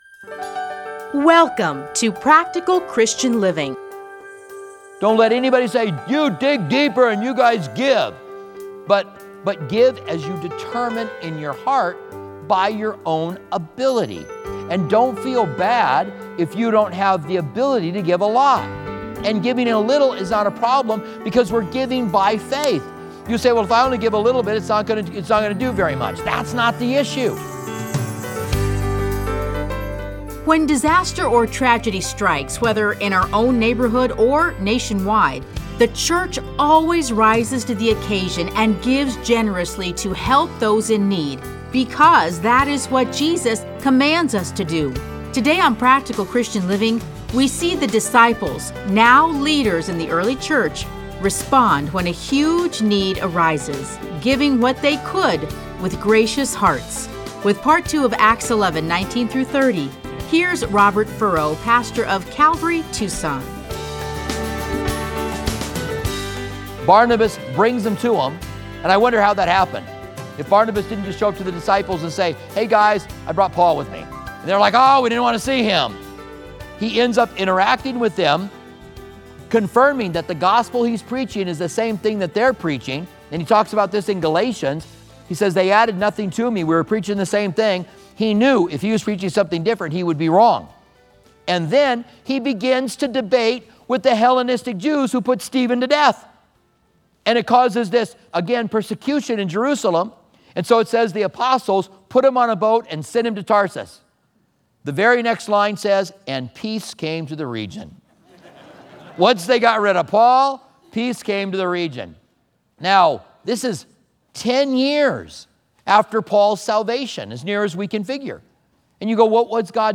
Listen to a teaching from Acts 11:19-30.